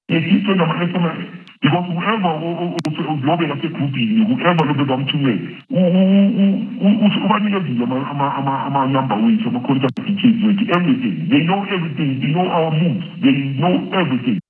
Speaking exclusively to Kaya News on condition of anonymity, a source says their security has been compromised and they are appealing to the police’s crime intelligence unit for protection.
THREATENED-COMMUNITY-MEMBER.wav